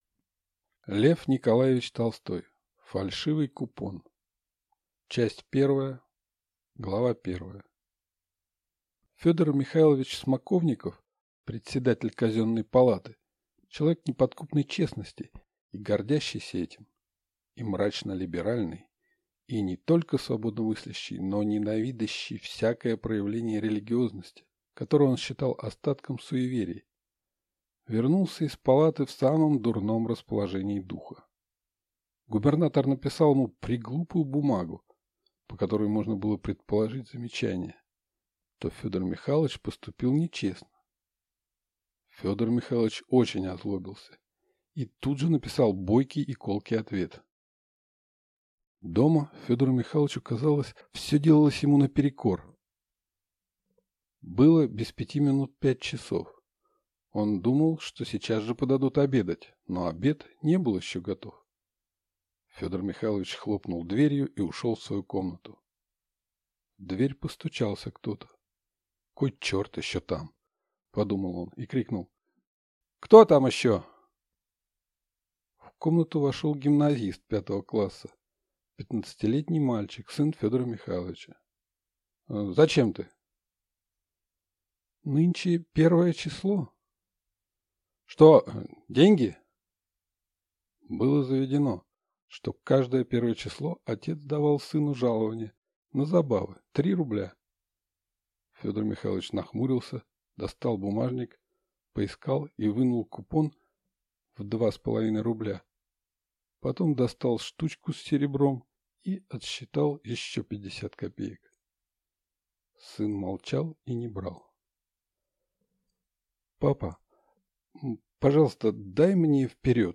Аудиокнига Фальшивый купон | Библиотека аудиокниг